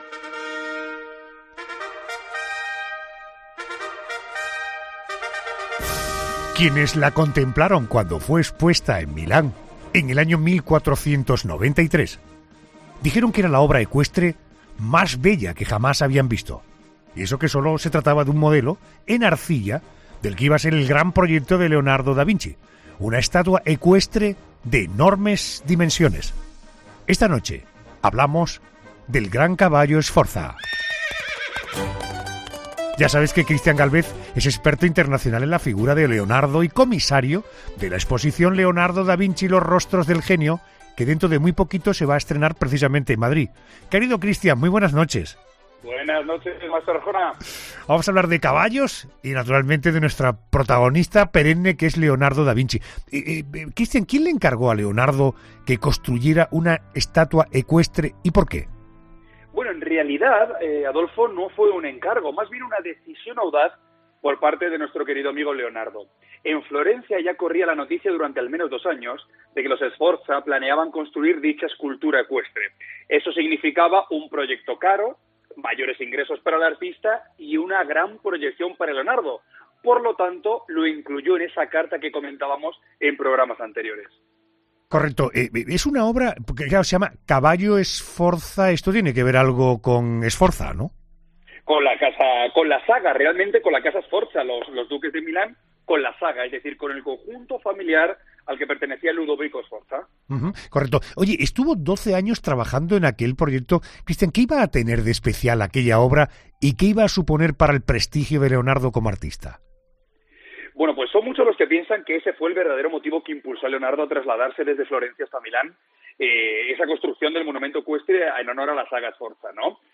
Christian Gálvez, experto en Leonardo da Vinci, ha pasado por 'La Noche de COPE' para explicar uno de los proyectos más importantes de la vida del genio